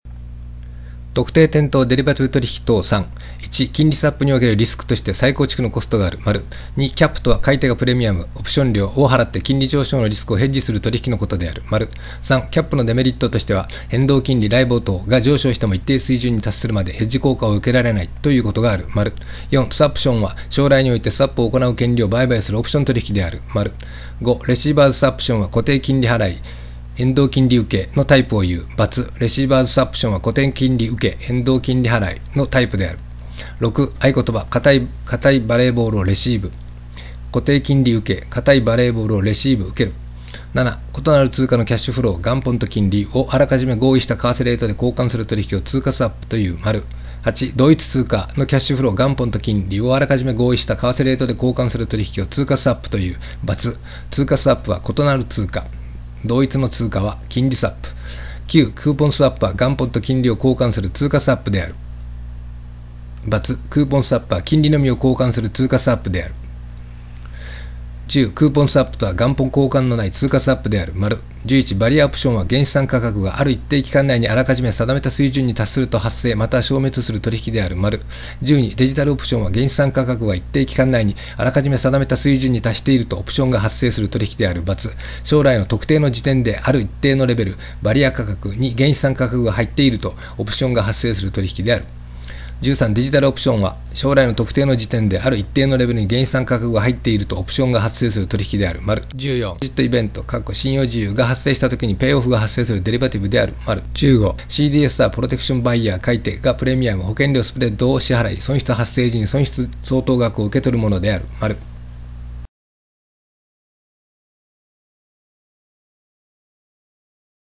（中年オヤジのダミ声での録音ですが、耳から聴いて覚えたいという方はご活用ください）